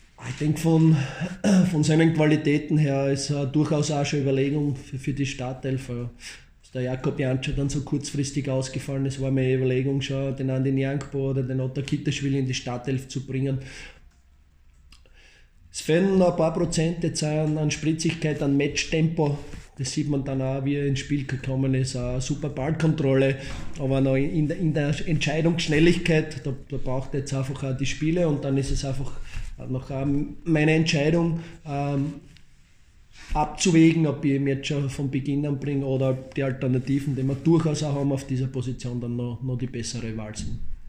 Stimmen: Wolfsberger AC vs. SK Sturm Graz